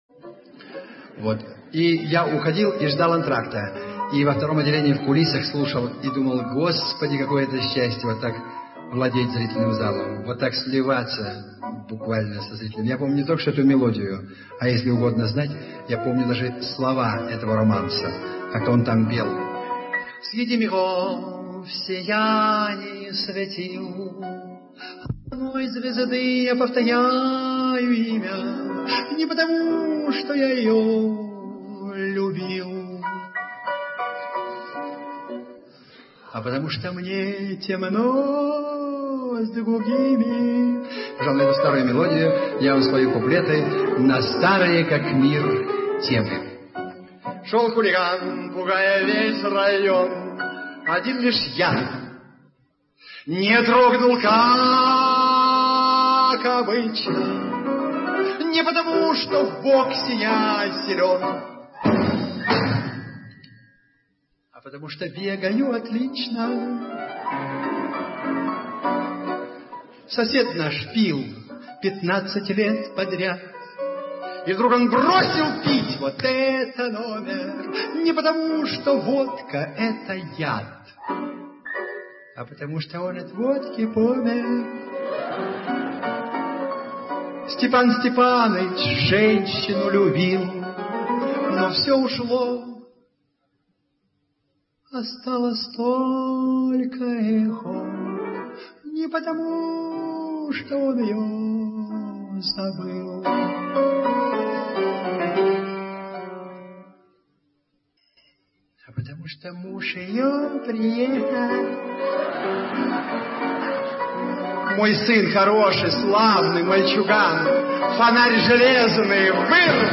Сатирические куплеты